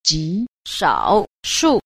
1. 極少數 – jí shǎoshù – cực thiểu số (rất ít)